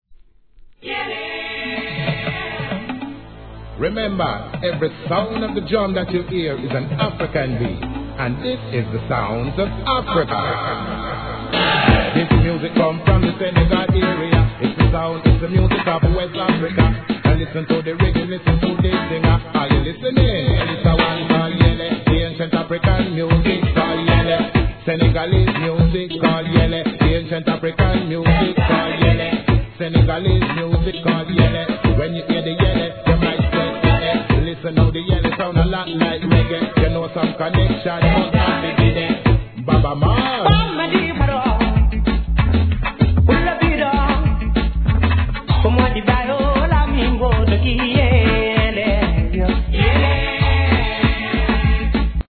1. REGGAE
RAGGA AFRICA-STYLE!